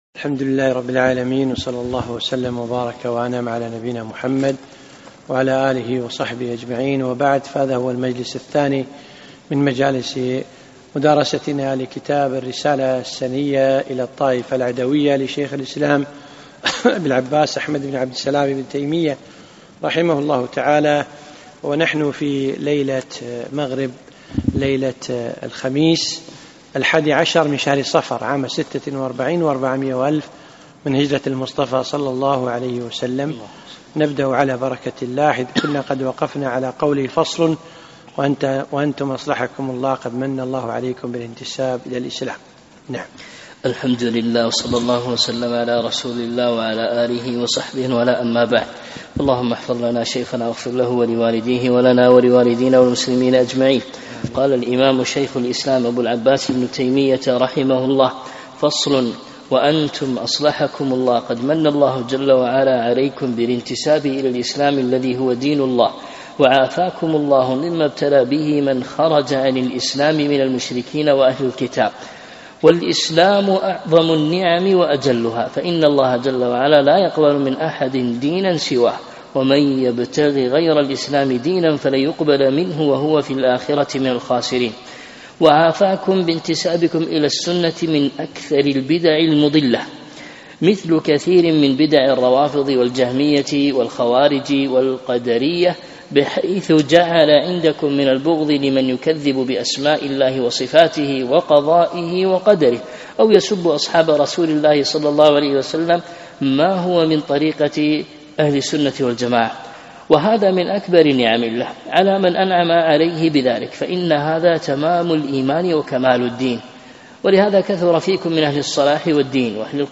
محاضرة - هموم لغوي